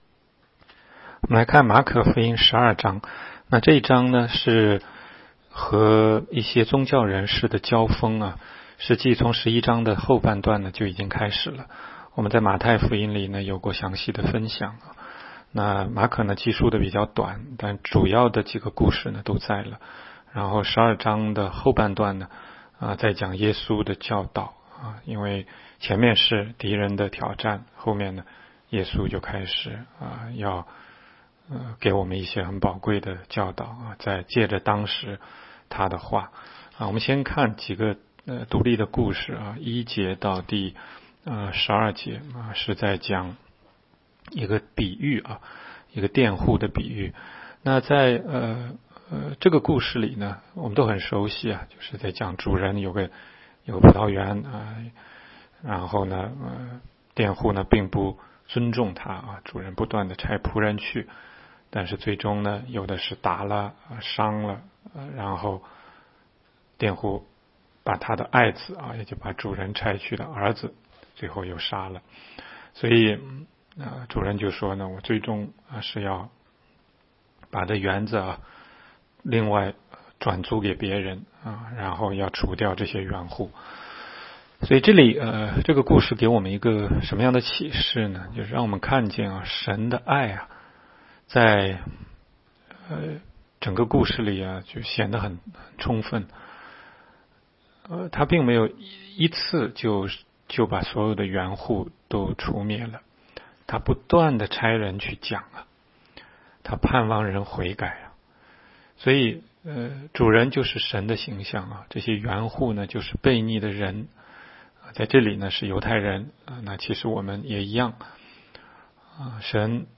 16街讲道录音 - 每日读经-《马可福音》12章